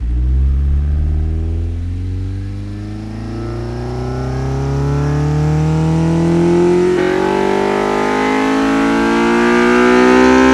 rr3-assets/files/.depot/audio/Vehicles/i4_05/i4_05_accel.wav
i4_05_accel.wav